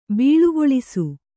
♪ bīḷugoḷisu